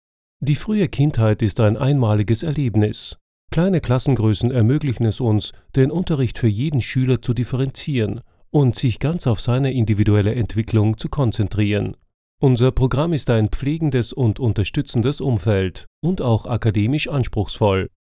Deutscher Sprecher, Off Sprecher,eigenes Studio vielseitige,warme, durchsetzungsfähige Stimme für Werbung, Dokumentation, Fernsehbeiträge, Trailer,e-learning, Imagefilm, Lyrik und Hörbuch
Sprechprobe: eLearning (Muttersprache):
versatile german voice over artist